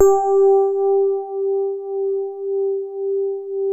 FINE SOFT G3.wav